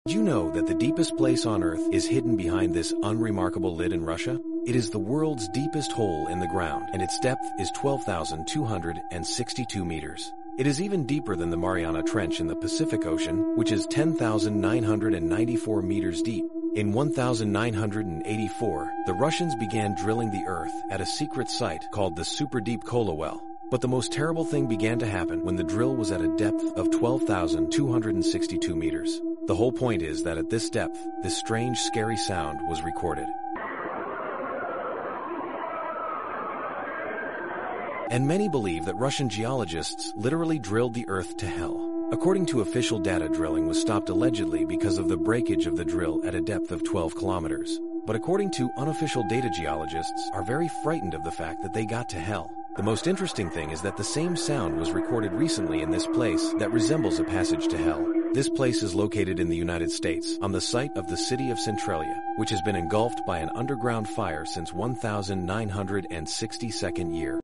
kola superdeep borehole on kotrrc recorded sounds of hell